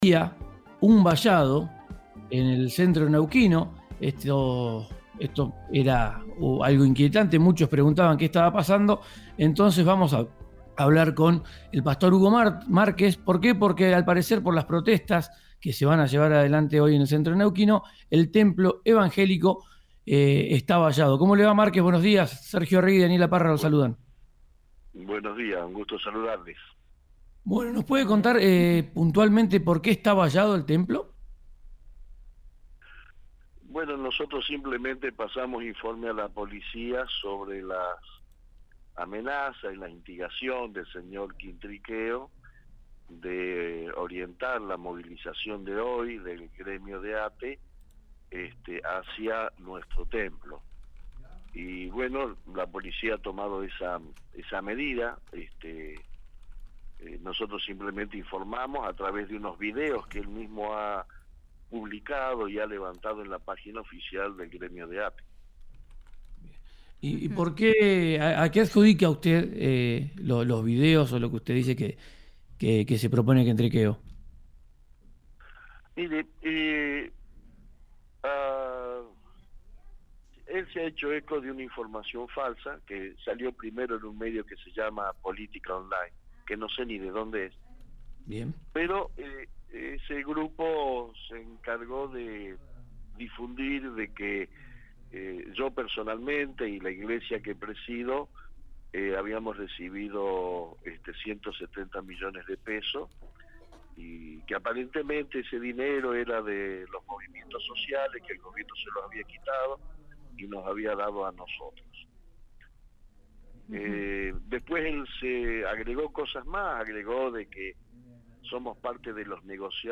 En diálogo con «Ya es tiempo» en Río Negro Radio apuntó contra el dirigente gremial de involucrarlos «con información falsa».